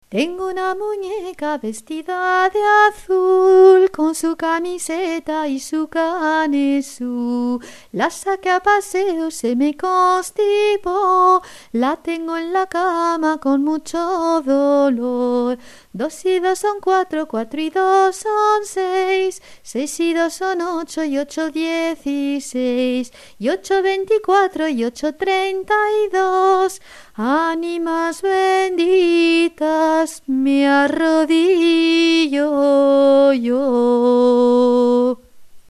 canta una canción infantil muy famosa.